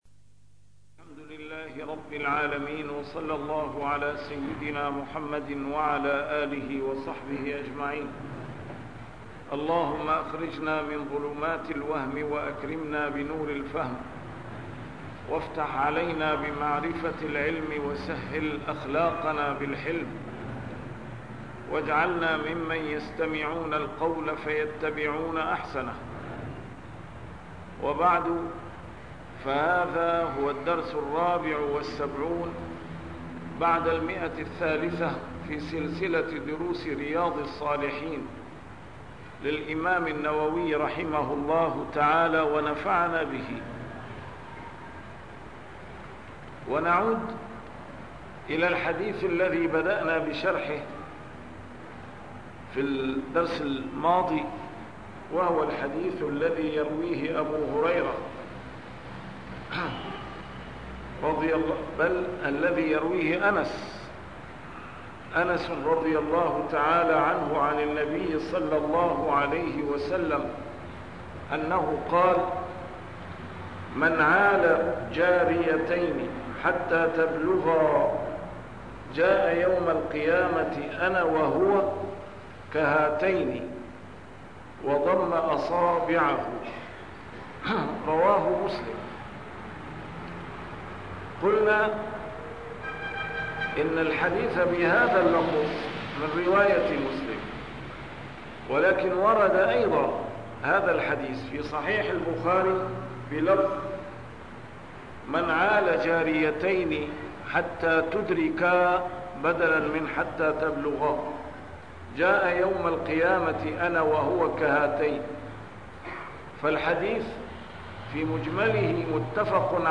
A MARTYR SCHOLAR: IMAM MUHAMMAD SAEED RAMADAN AL-BOUTI - الدروس العلمية - شرح كتاب رياض الصالحين - 374- شرح رياض الصالحين: ملاطفة اليتيم والبنات